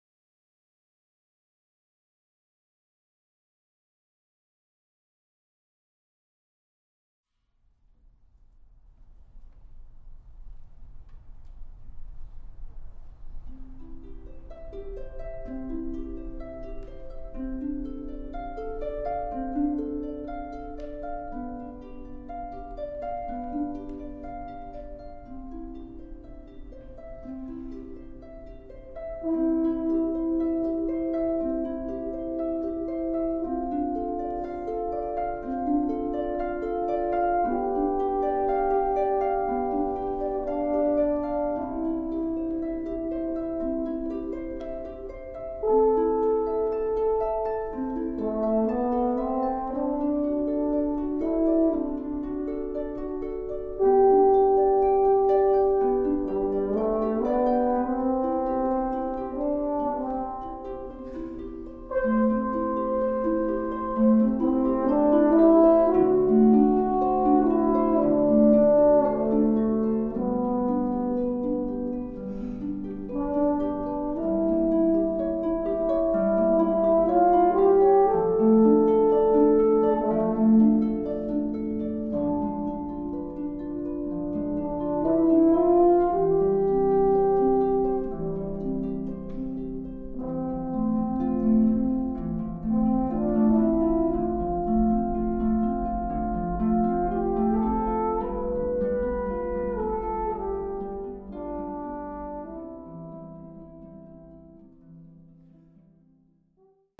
Voicing: Horn Solo